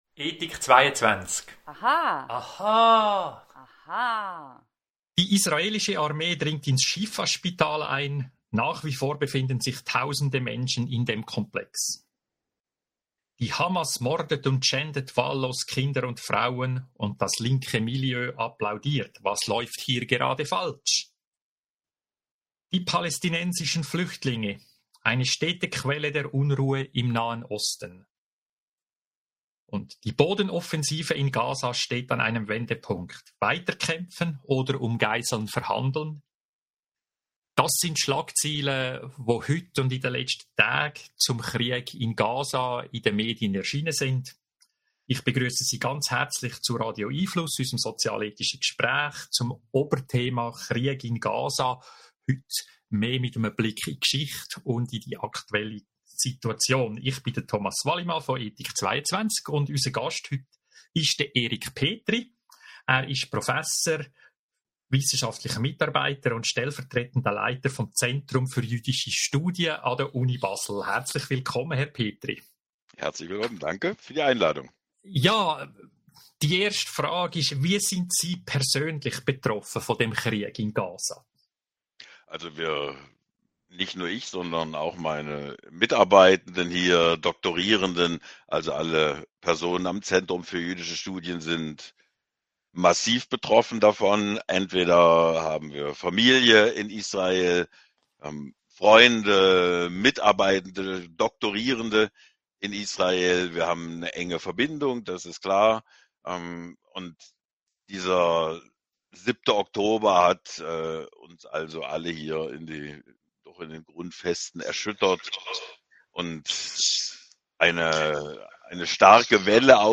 zum Thema hören Sie im Podcast unseres Gesprächs vom 15. November 2023